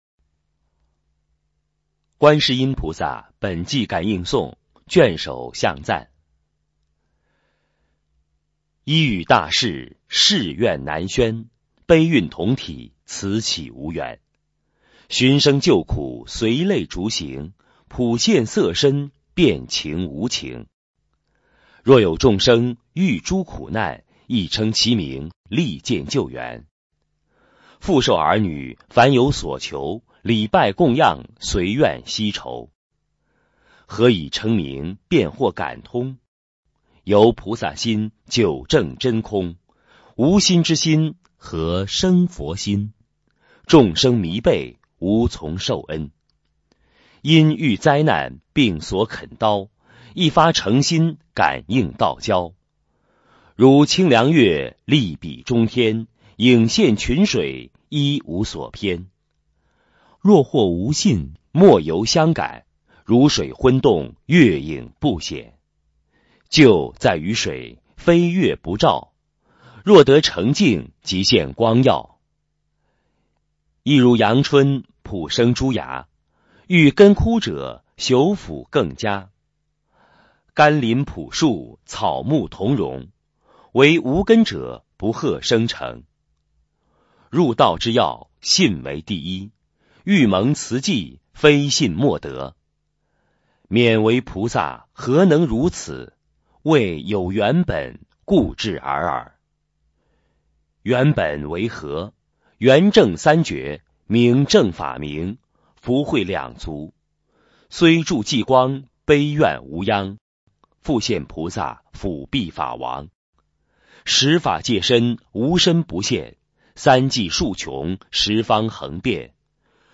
佛音 诵经 佛教音乐 返回列表 上一篇： 金刚经(唱颂